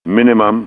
minimum.wav